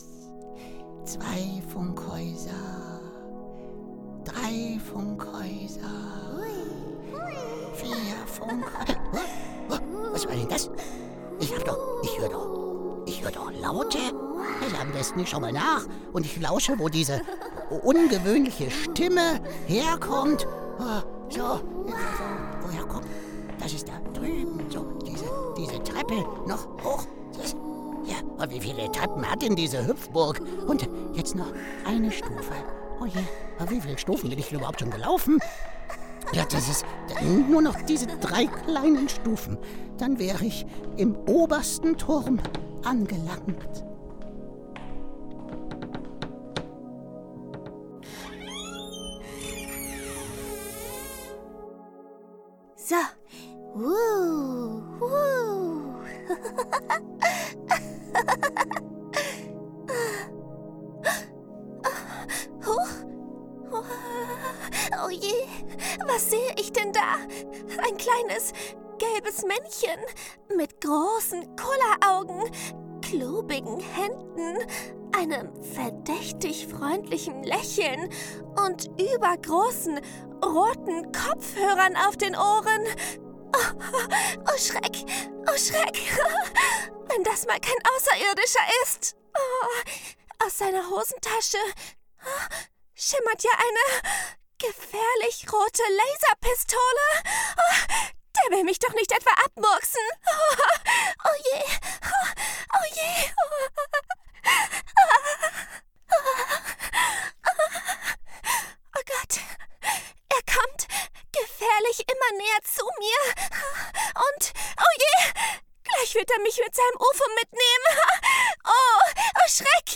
Hörspiel - Tina und das Funkhausmännchen
Radiohoerspiel_Maya-und-das-FHM.mp3